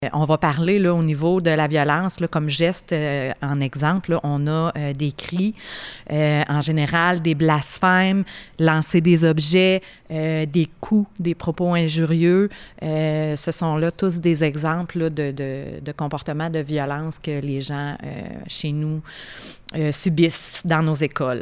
Lors de la conférence de presse de jeudi à Nicolet qui était organisée par la Fédération du personnel de soutien scolaire (FPSS-CSQ) lors de sa tournée des régions, il a été dit que 245 actes ont été dénoncés.